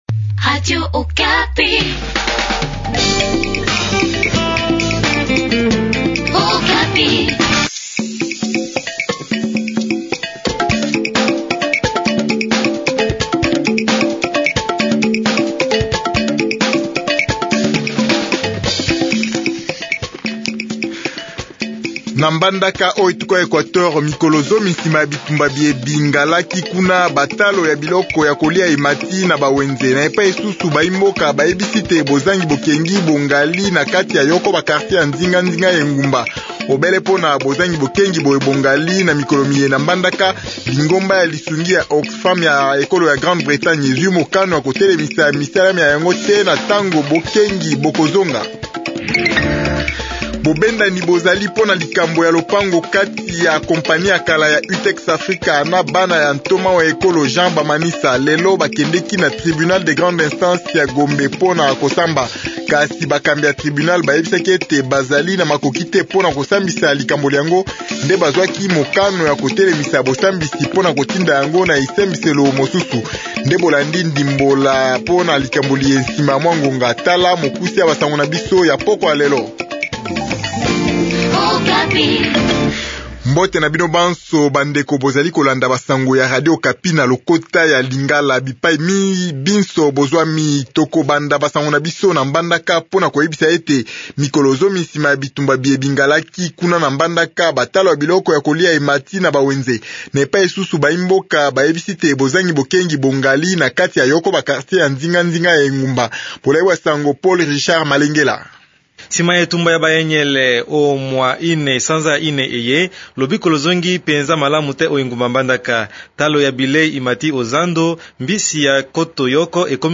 Journal lingala du soir